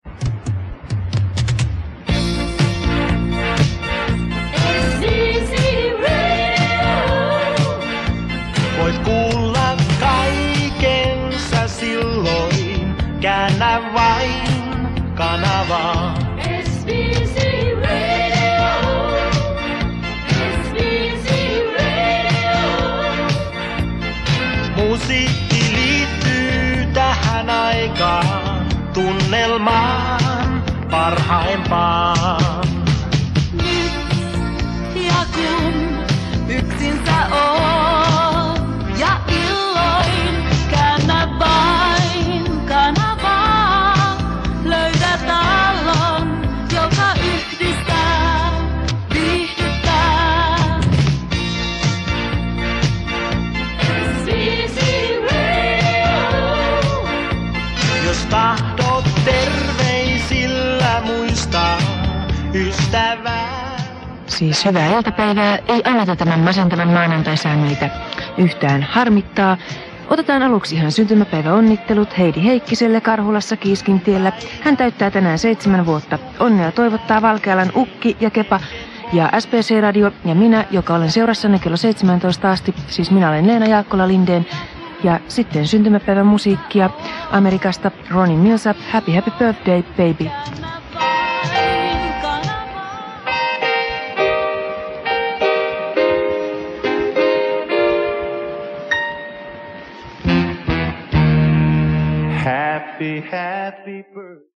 SBC Radion tunnuslaulu ja juontoa 1986.
SBC-Radio-tunnari-ja-juonto-1986.mp3